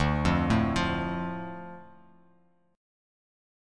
Dm Chord
Listen to Dm strummed